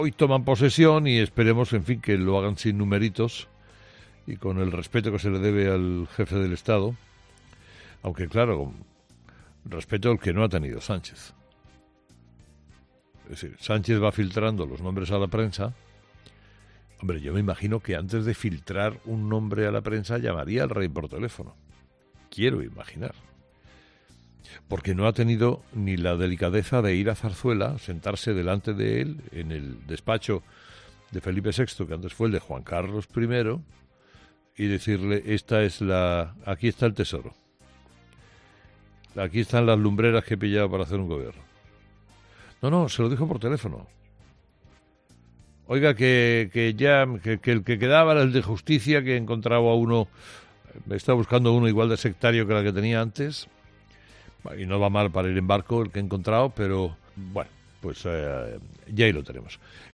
Aquí están las lumbreras que he pillado para hacer un Gobierno”, ha comenzado diciendo con ironía Herrera.